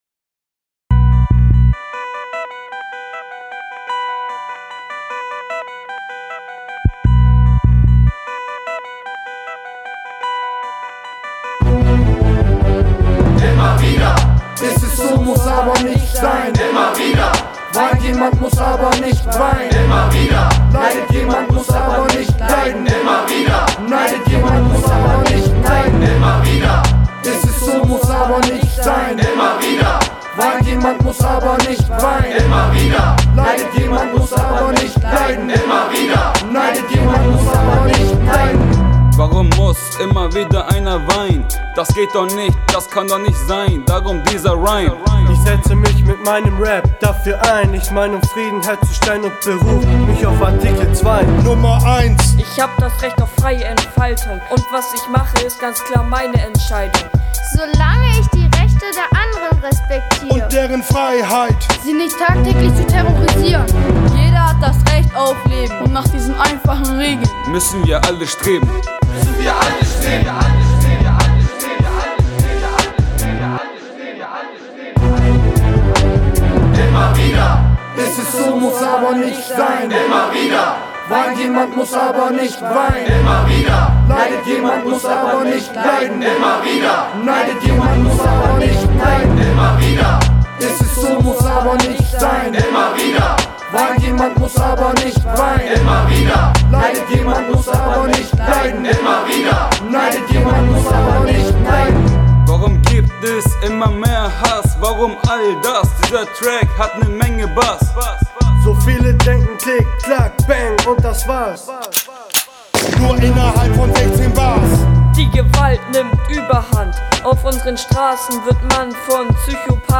ISL-Kurs präsentiert Rap in der Volkshochschule
Er war im Rahmen eines Schülerakademie-Projekts entstanden und wurde im Dezember der Öffentlichkeit präsentiert: Ein Rap von Schülern der ISL mit dem Titel "IMMER WIEDER"